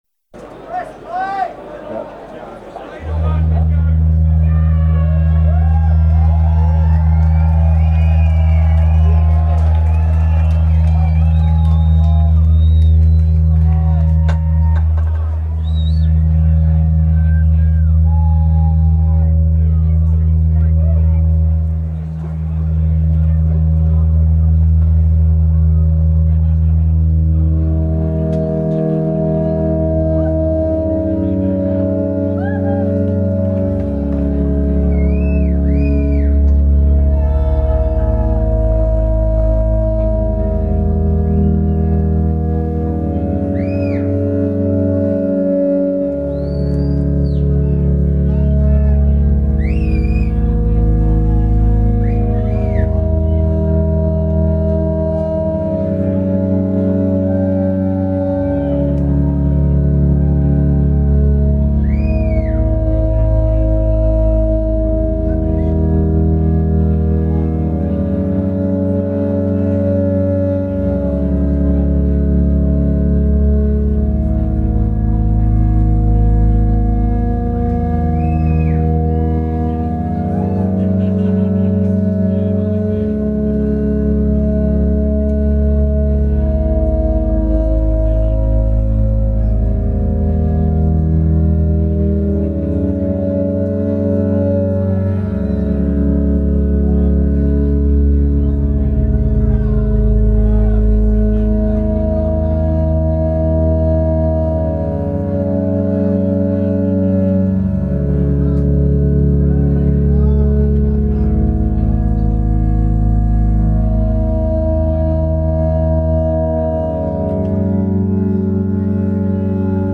location Sussex, UK venue Camber Sands